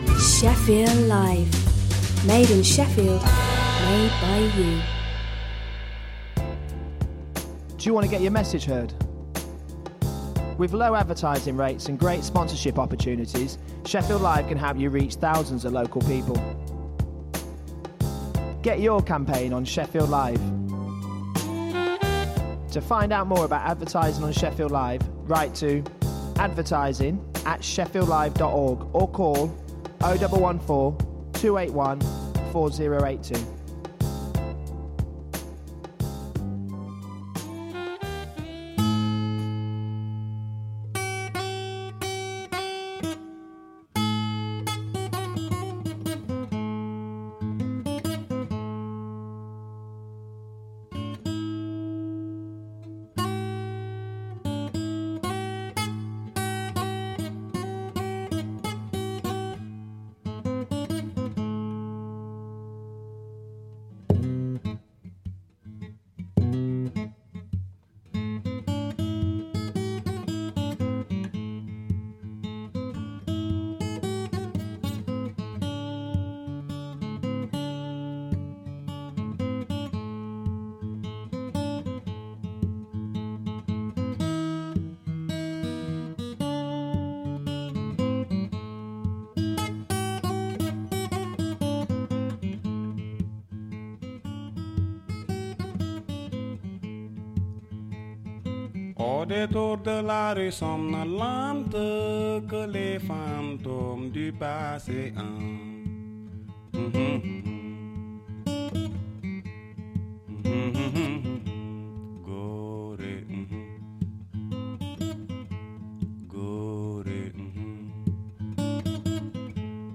Magazine programme for the Yemeni community